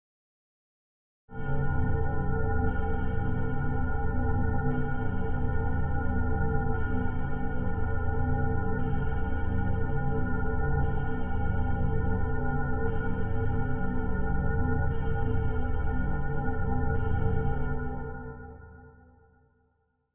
空间站门
描述：这是一个科幻门或设备的声音短促的哔哔声它是用Mixcraft 6和shure usb麦克风创作的。我吹口哨，用效果和音高控制来操纵声音。
标签： 明星跋涉 空间站门 科幻 外星人 空间 科幻
声道立体声